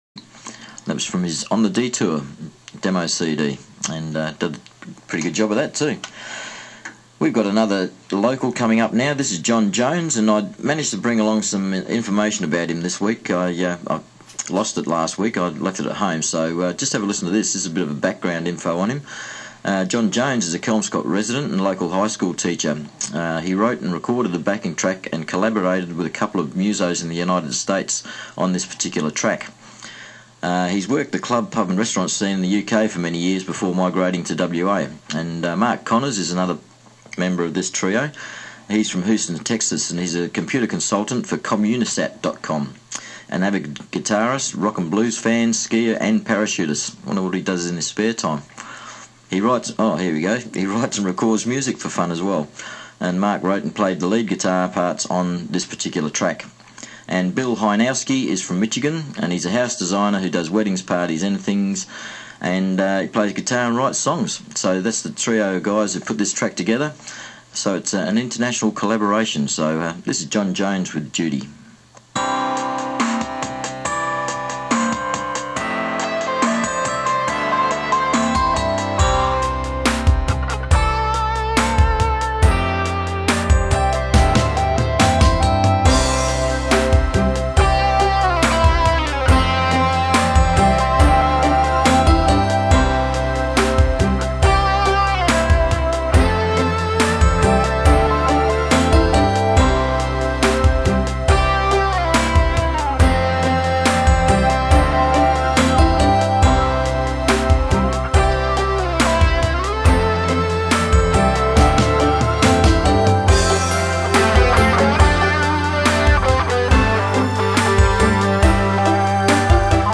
This is an internet collaboration
I then spliced it into the front end of the song.
Electric Guitars
with radio intro